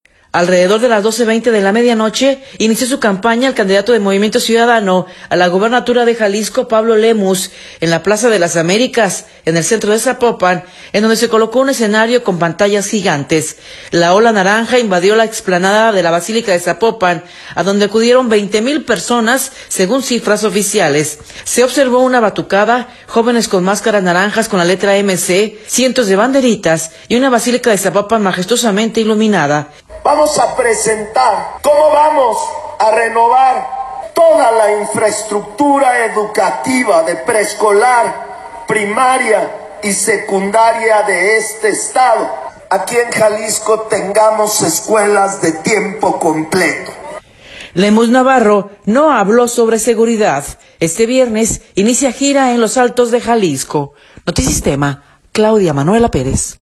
Alrededor de las 12:20 de la medianoche inició su campaña el candidato de Movimiento Ciudadano a la gubernatura de Jalisco, Pablo Lemus, en la Plaza de las Américas en el Centro de Zapopan, en donde se colocó un escenario con pantallas gigantes.